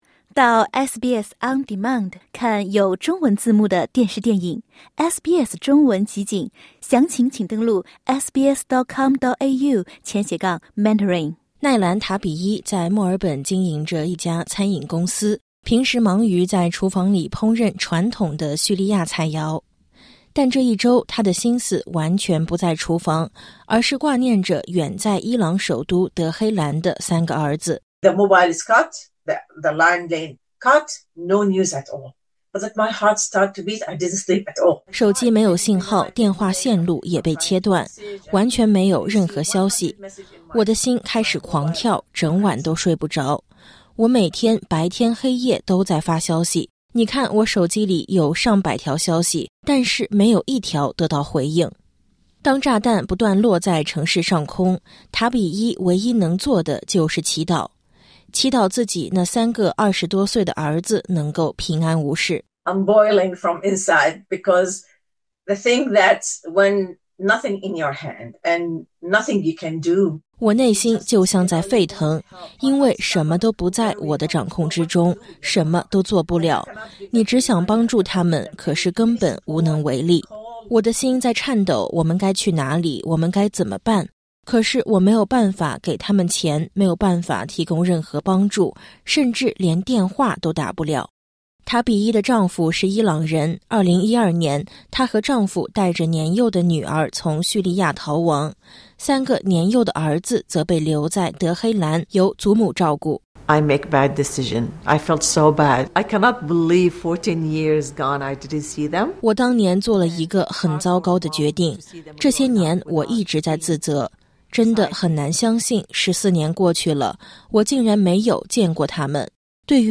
她和身在伊朗的三个儿子分隔两地。当电话和网络全部中断时，她甚至无法确认孩子们是否还活着。点击音频，收听完整报道。